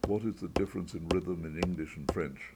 Performance Exercise Chapter 10 D
rhythmFast.aiff